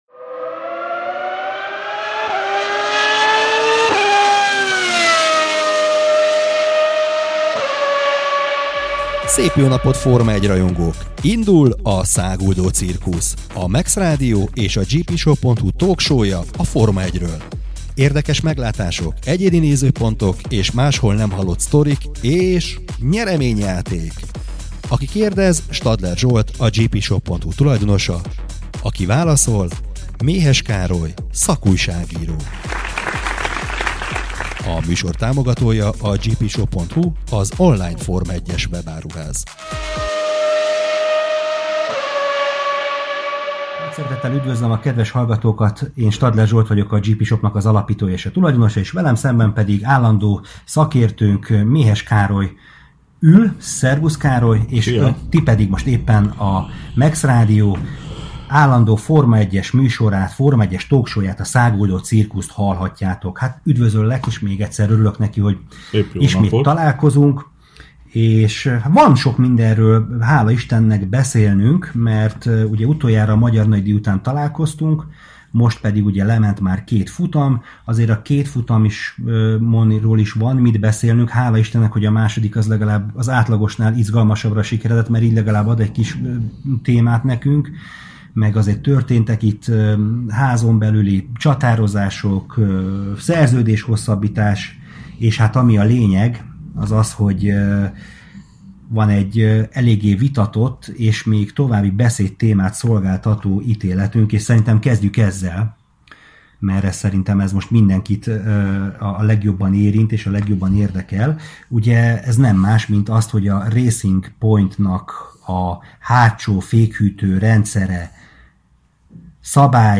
Ez a Száguldó Cirkusz, a GPshop Forma 1-es talk showjának 2020 augusztus 13-i adásának felvétele a Mex Rádióból.